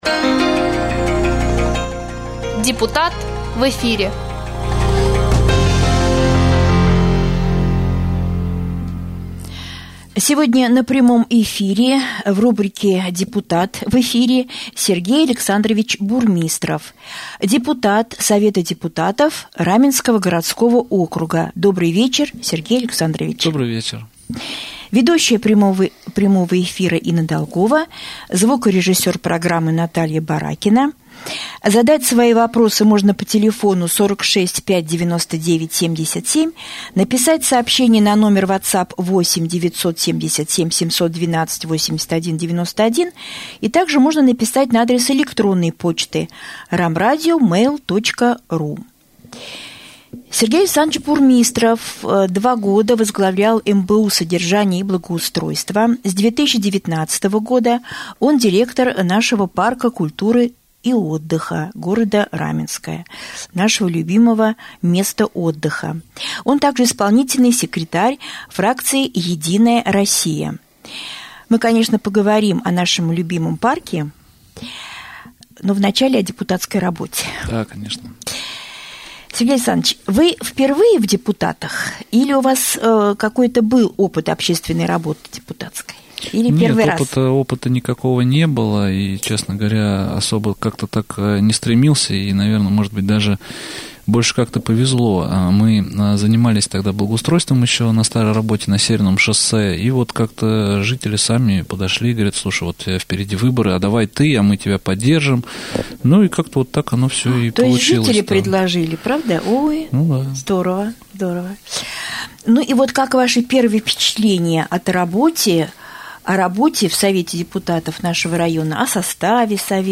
Депутат Совета депутатов Раменского г.о., директор Раменского городского парка Сергей Александрович Бурмистров стал гостем студии Раменского радио.
В прямом эфире обсудили вопросы: